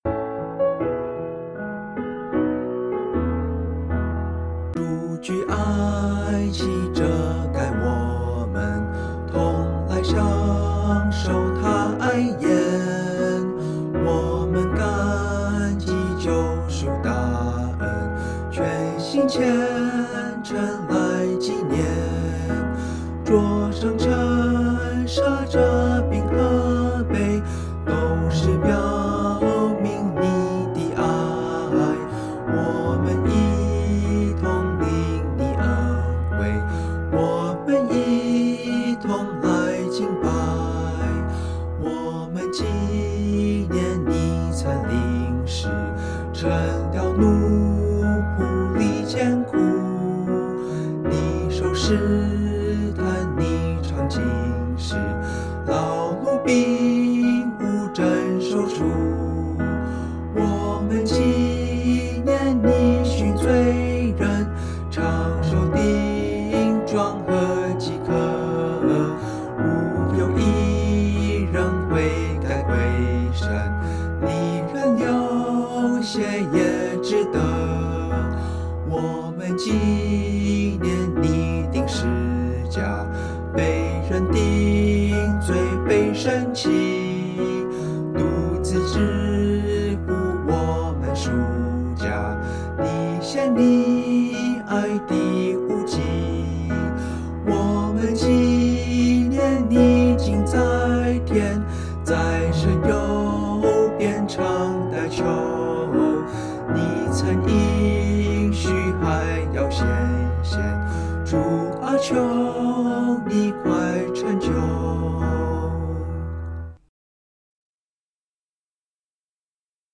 G大调